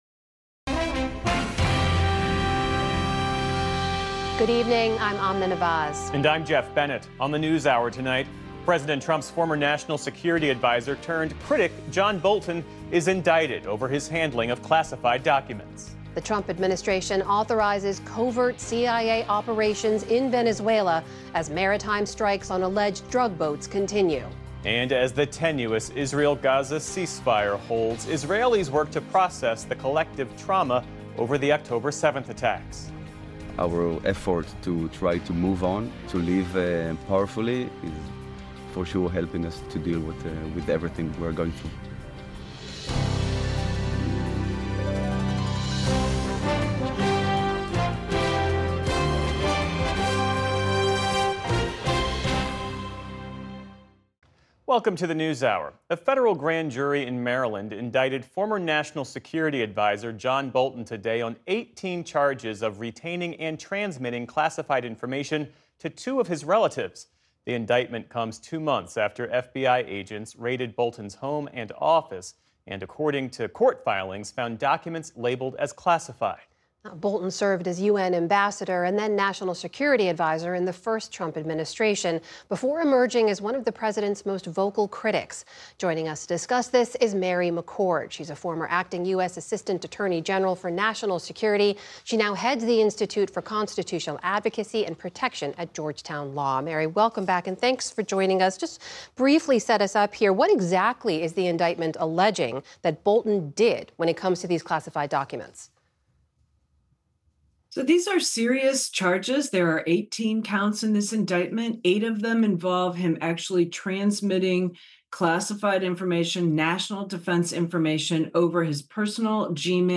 News, Daily News